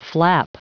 Prononciation du mot flap en anglais (fichier audio)
Prononciation du mot : flap